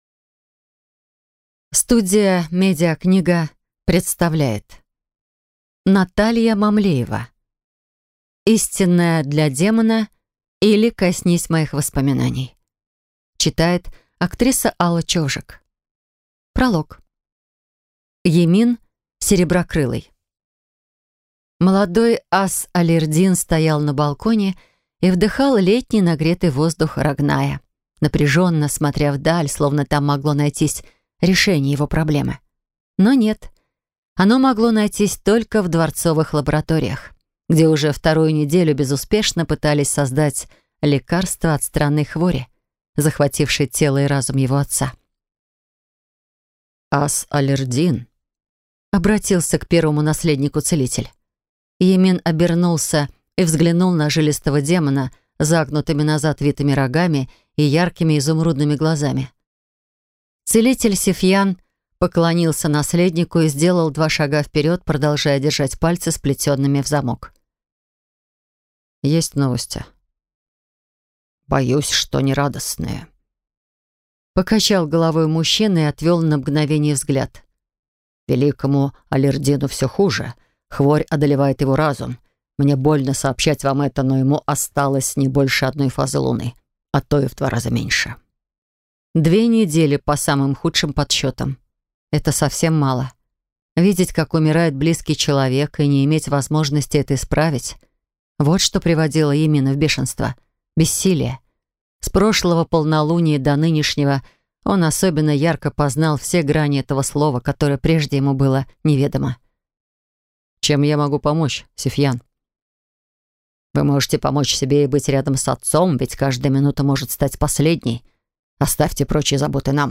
Аудиокнига Истинная для демона, или Коснись моих воспоминаний | Библиотека аудиокниг
Прослушать и бесплатно скачать фрагмент аудиокниги